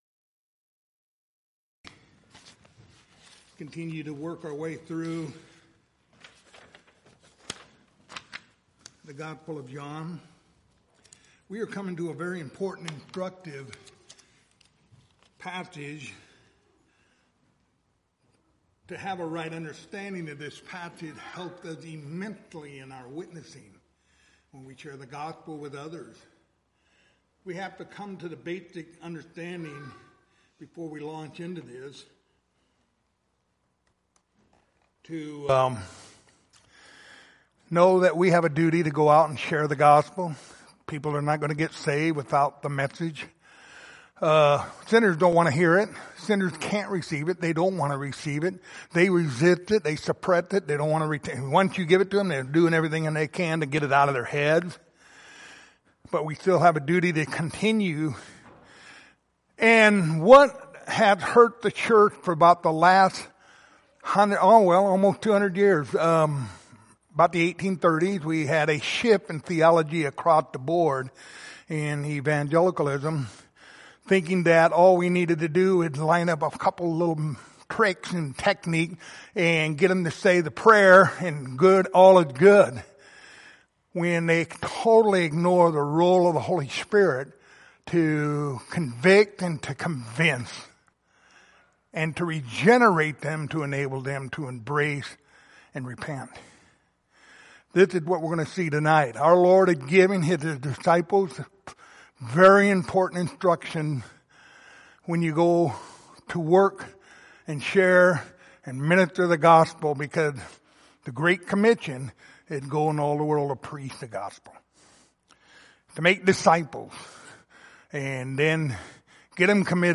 The Gospel of John Passage: John 16:8-11 Service Type: Wednesday Evening Topics